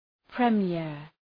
Προφορά
{prı’mıər, prə’mjeər}